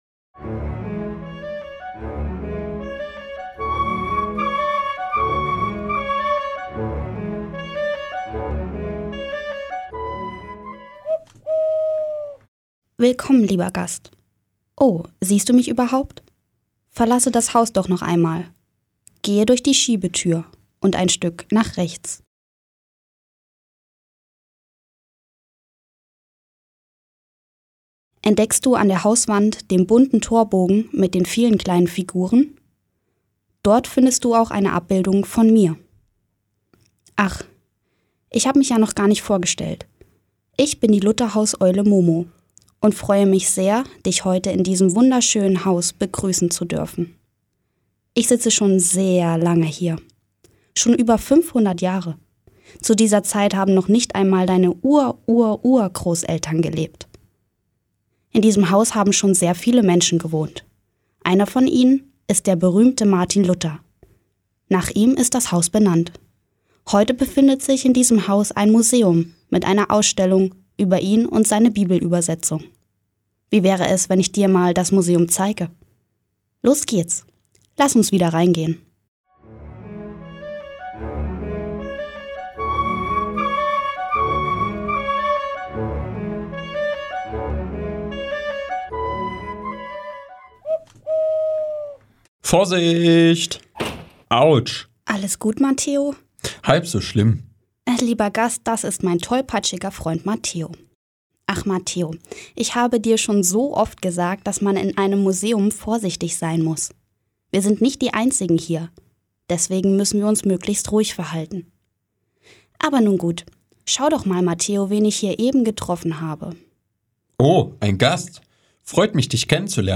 Audioguide für Kinder (51,6 MiB)